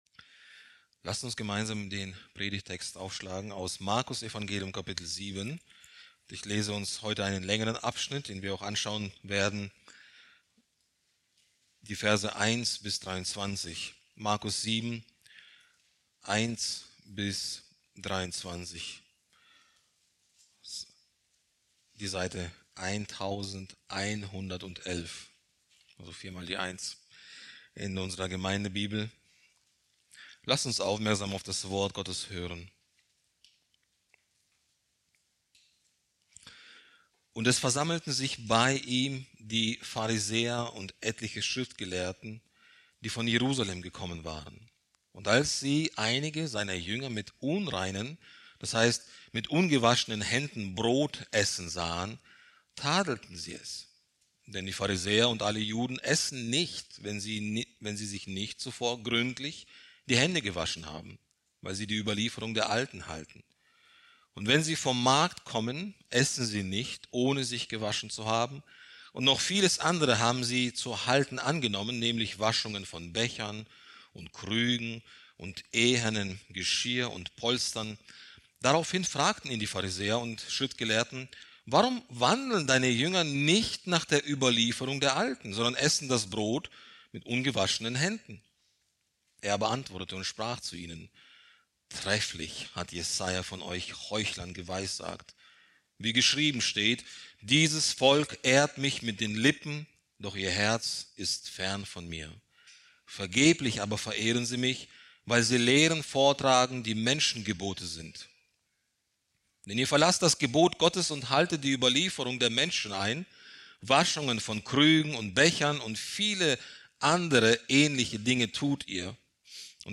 Predigt aus der Serie: "Evangelium nach Markus"